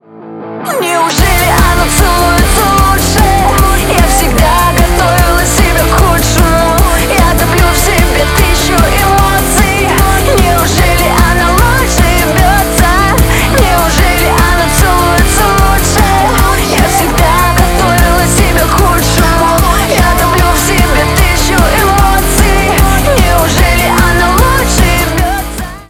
Поп Музыка
громкие